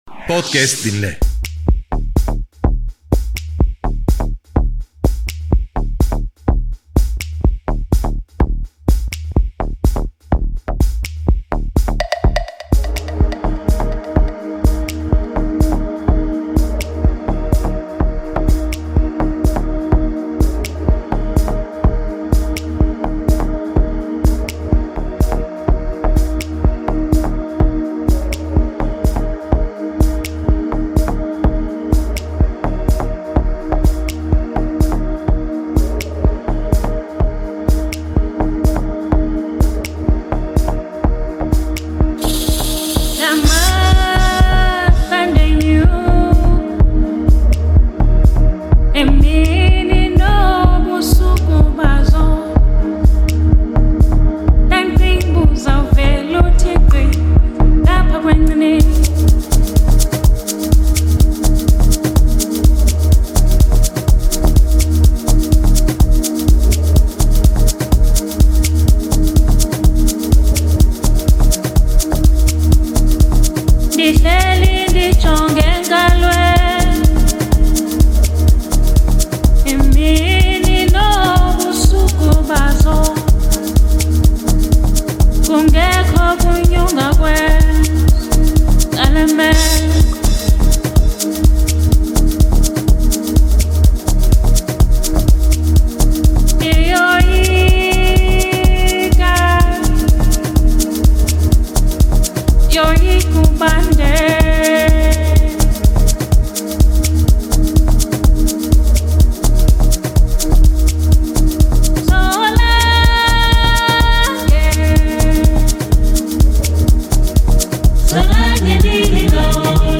House Set